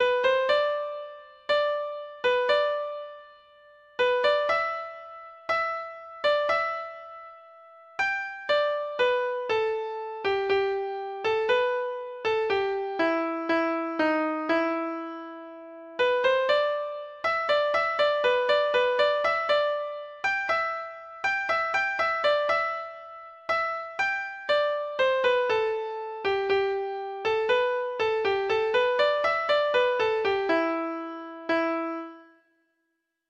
Folk Songs from 'Digital Tradition' Letter A Awa' Whigs Awa'
Treble Clef Instrument  (View more Intermediate Treble Clef Instrument Music)
Traditional (View more Traditional Treble Clef Instrument Music)